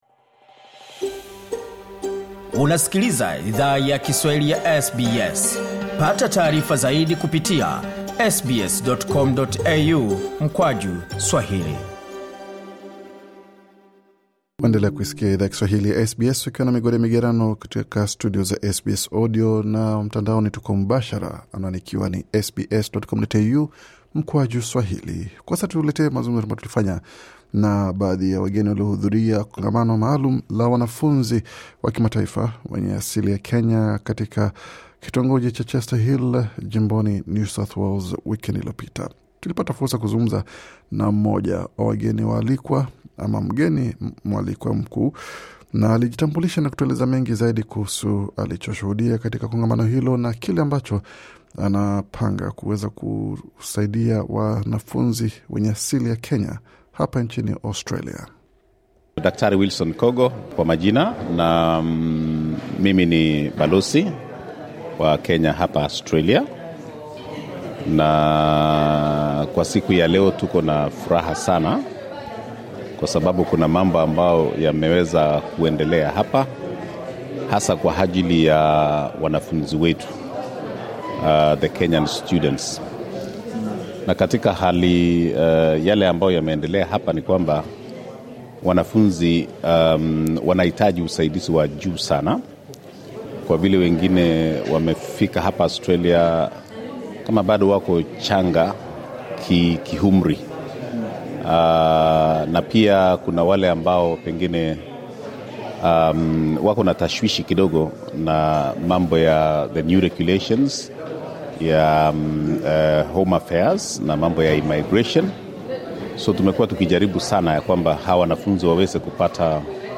Katika mazungumzo maalum aliyo fanya na SBS Swahili, Balozi wa Kenya nchini Australia Mhe Dkt Wilson Kogo, alitoa wito kwa mamlaka husika iwa ondolee wa Kenya sharti hilo pamoja naku tambua taifa hilo kama nchi inayo zungumza Kiingereza. Mhe Dkt Kogo alifunguka pia kuhusu uwakilishi mwingine anao wafanyia wakenya, nchini Australia.